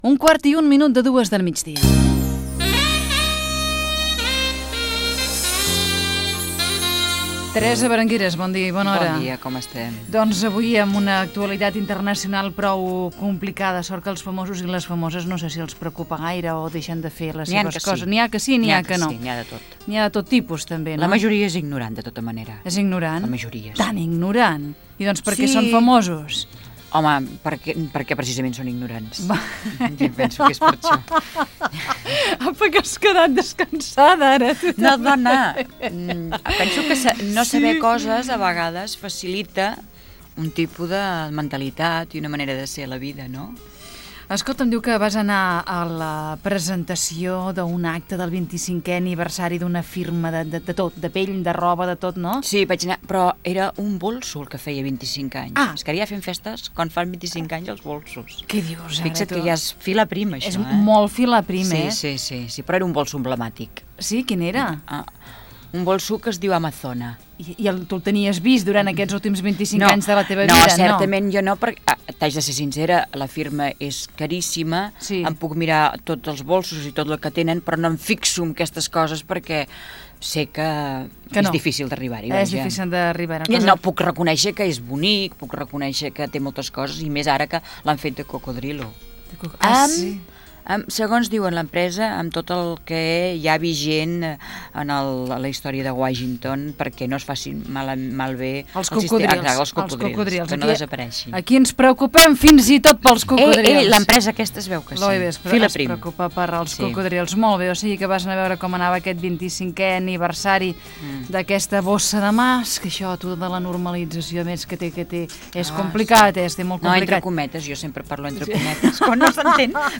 Entreteniment
FM
Fragment extret de l'arxiu sonor de COM Ràdio.